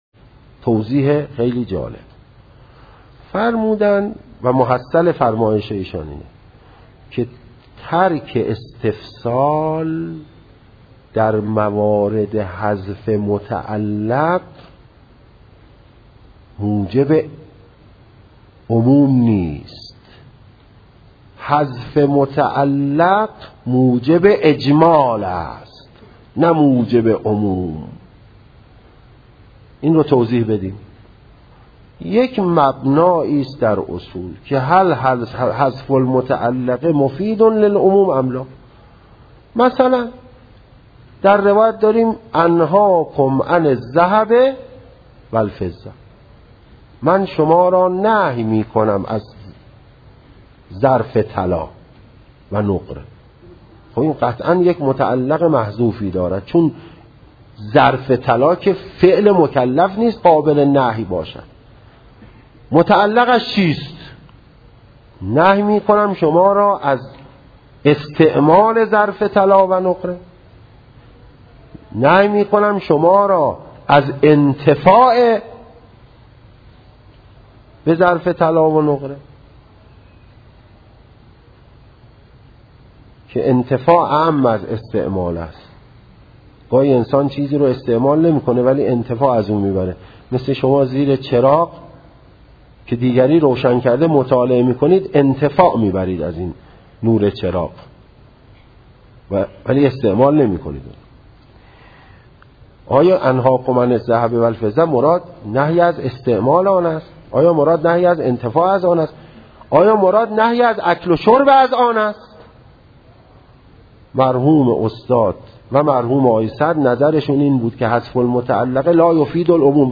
درس خارج